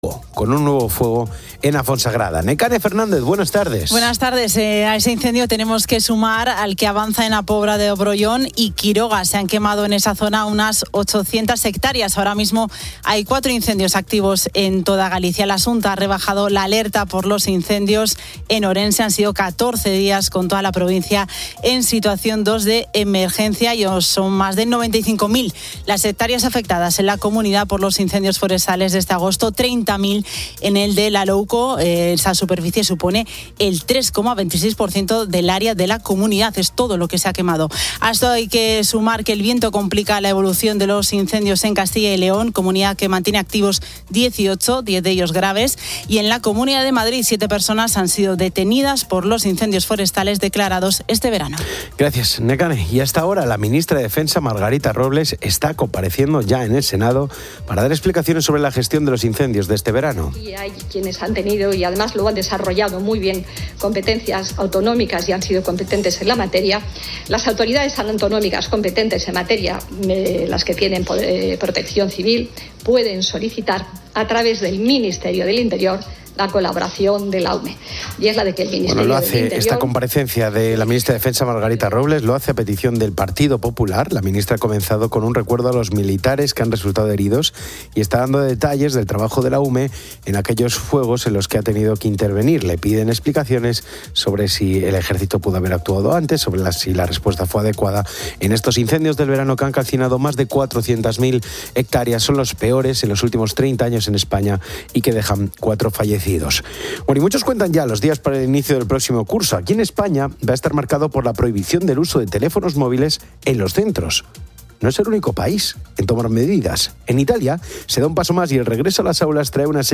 La radio informa sobre varios incendios forestales en España.